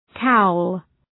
Shkrimi fonetik {kaʋl}